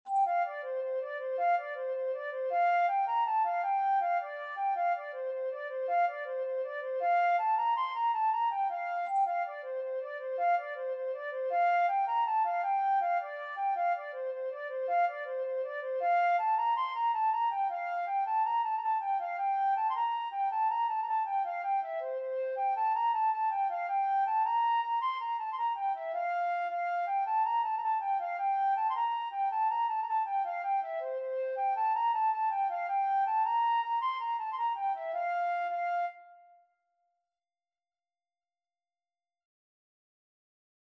Flute version
6/8 (View more 6/8 Music)
C6-C7
Flute  (View more Easy Flute Music)
Traditional (View more Traditional Flute Music)